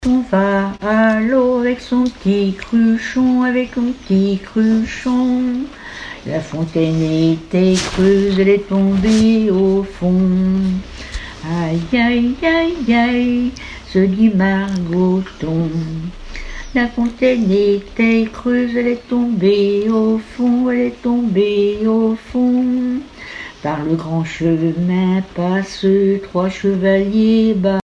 Chansons populaires
Pièce musicale inédite